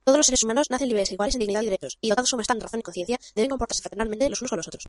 Here’s this recording at twice the normal speed.
udhr_es-fast.mp3